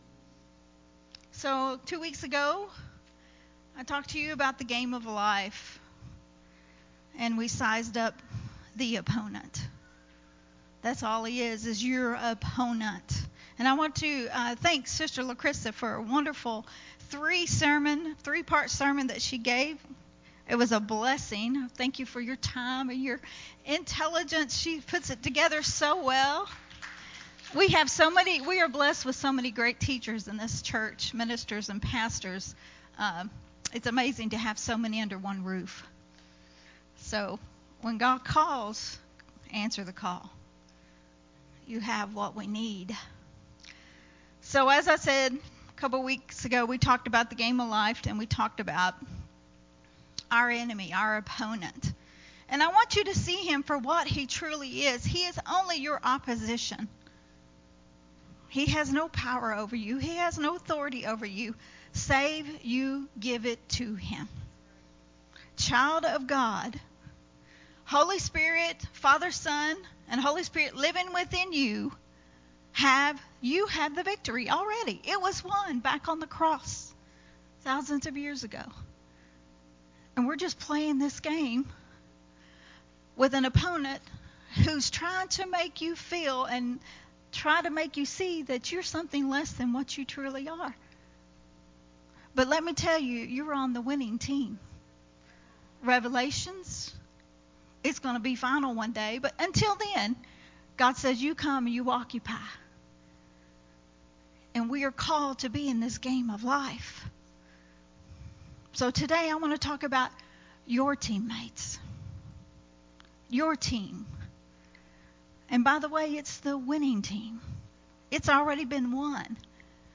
recorded at Unity Worship Center on September 4th, 2022